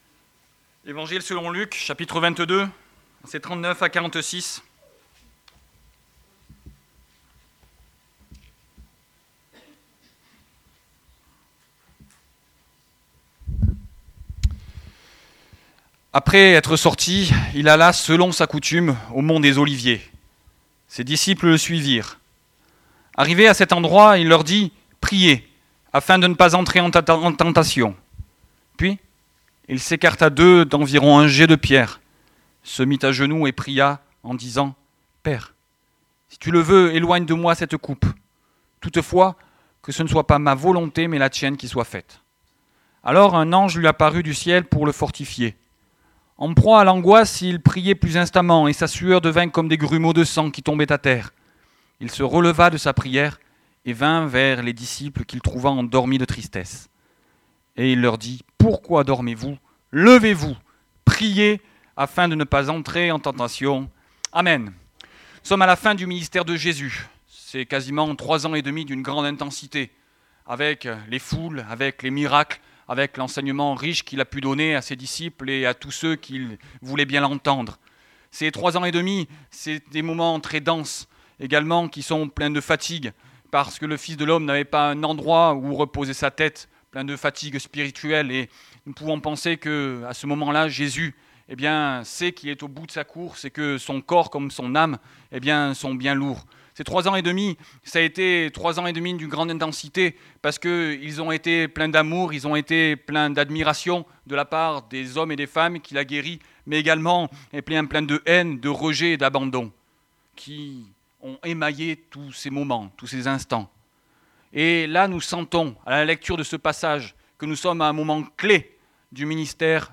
Message audio